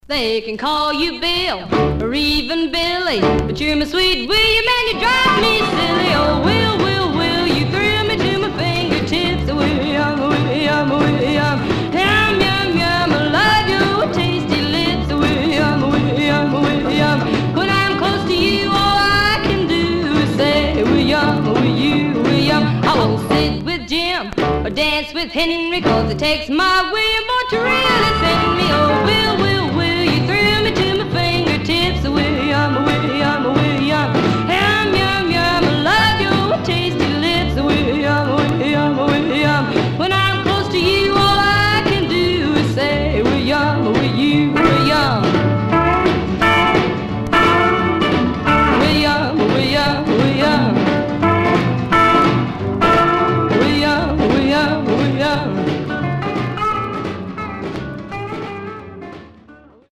Mono
Rockabilly